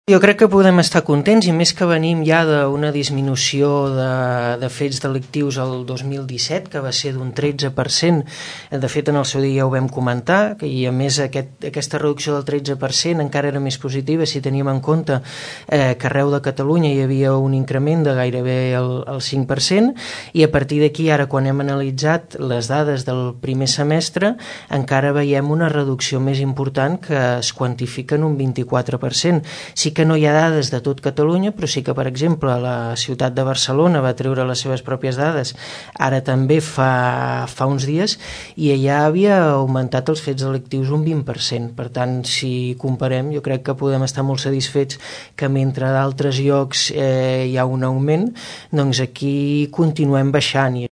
El regidor de Seguretat, Xavier Martin valora molt positivament les dades.